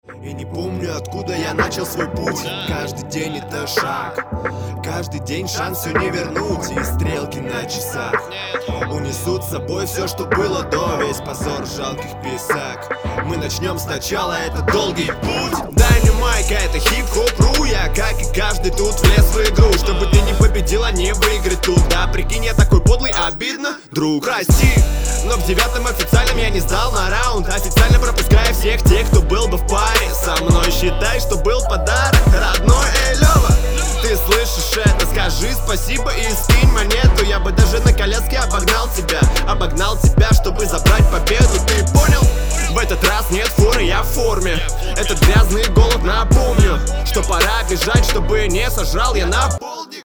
Нормуль, довольно интересно по исполнению, по тексту сносно, но, конечно, надо бы подбавить.
И техника видна, и текст с юморком.